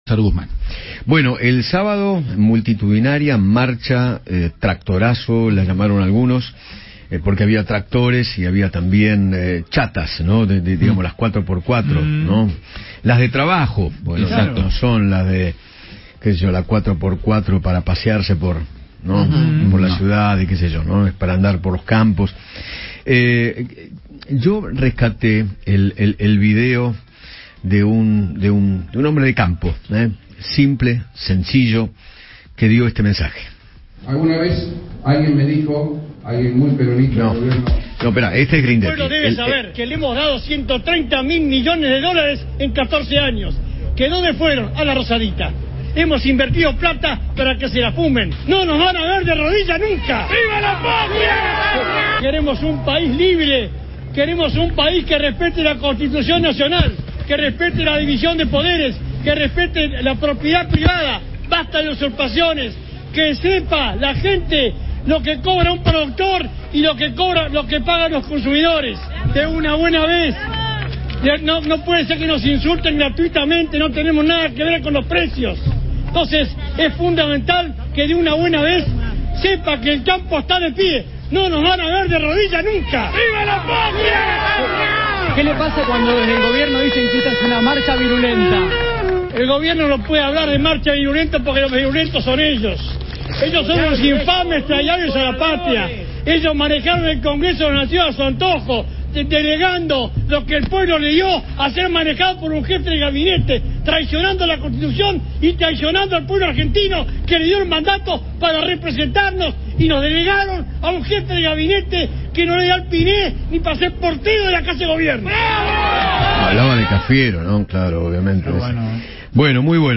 Néstor Grindetti, intendente de Lanús, conversó con Eduardo Feinmann sobre la presentación de su partido “Hacemos Juntos” que lanzó en compañía con Julio Garro y explicó sus dichos contra La Cámpora.